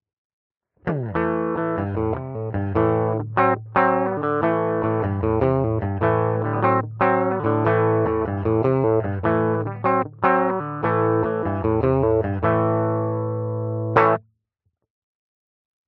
Allerdings fahre ich den Marshall hierbei im Overdrive-Channel, mit nicht ganz so viel Gain (ca. auf 10 Uhr).
Eine fette Paula auf Knopfdruck.
52er Gibson Les Paul Goldtop, Humbucker Steg:
di_gibson_52er_les_paul_goldtop_b_rockt.mp3